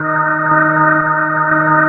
87 DRONE  -L.wav